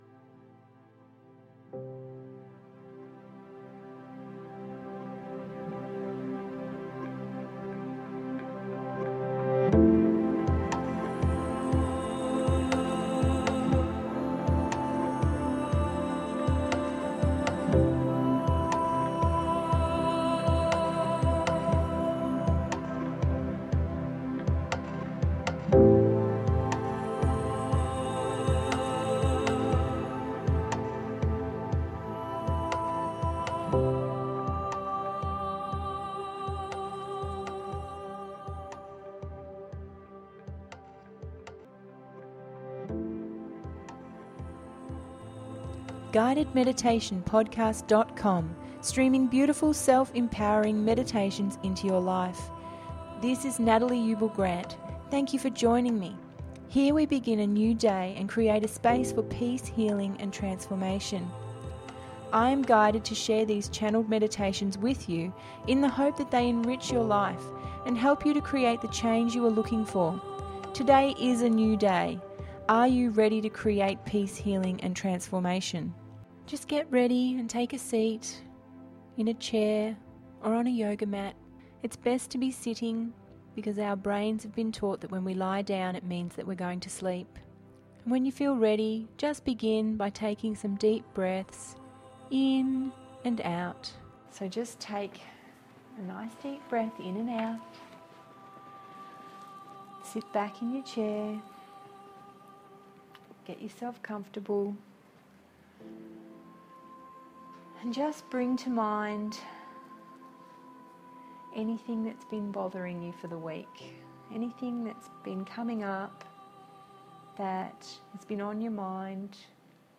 Emotional Release On The Beach…052 – GUIDED MEDITATION PODCAST